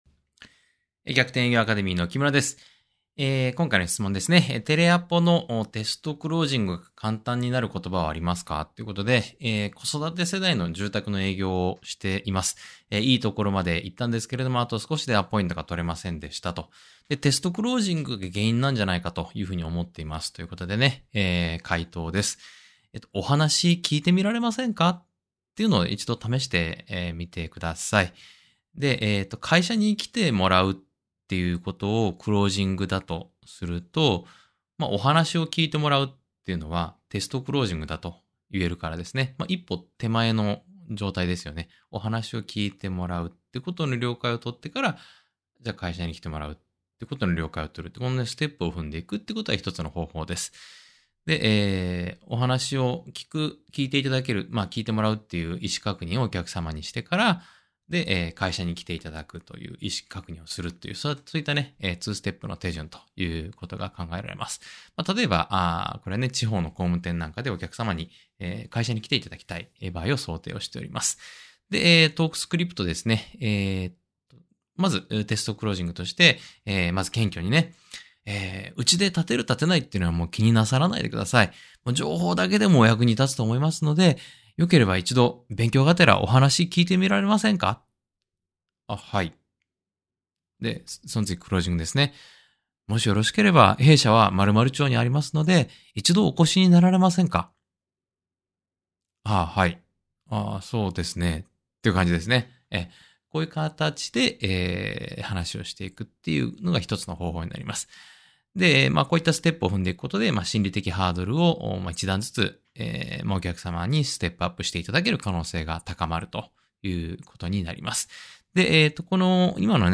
音声回答（︙をクリック→ダウンロード）